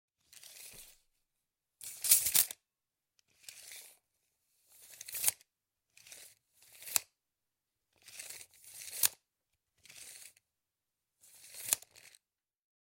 描述：拉出并放下金属卷尺，使其重新贴回其外壳中。
Tag: 卷轴 回缩 测量 磁带 拍击 金属 工具 弹簧